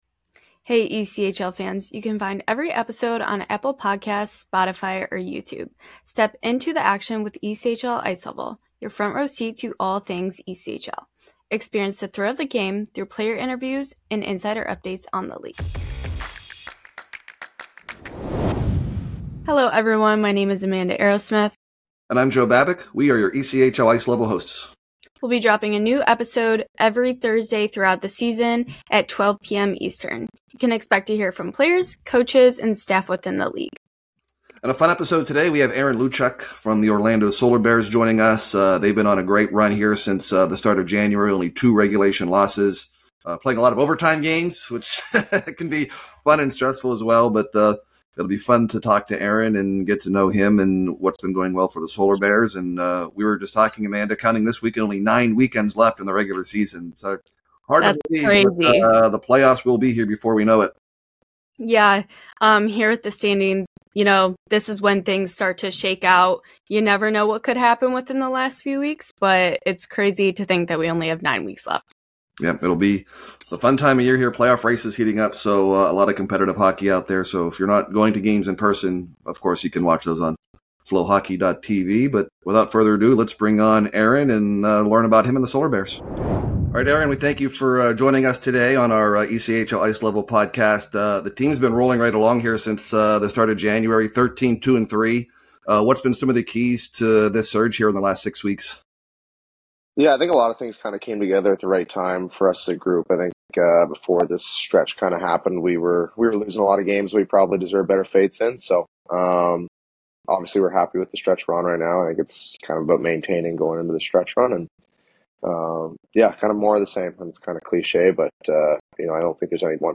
Step into the action with ‘ECHL Ice Level’, your front-row seat to all things ECHL. Experience the thrill of the game through player interviews, and insider updates on the league.